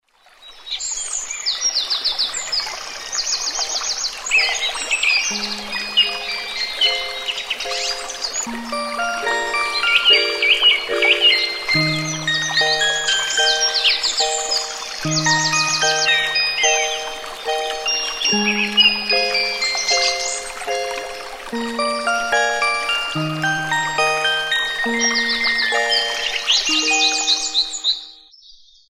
朝に聴きたいクラシック音楽と爽やかな小川と小鳥のBGMで清々しい目覚めに。